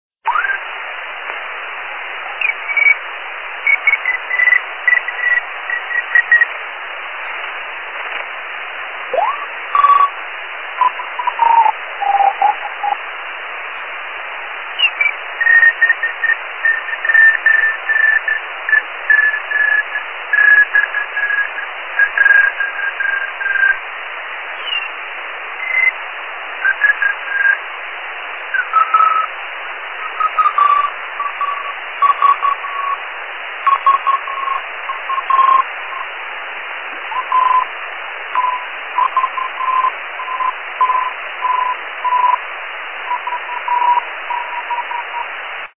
A 02h00, Swisscube arrive tr�s bien mais je n'arrive pas � donner une �l�vation � l'antenne.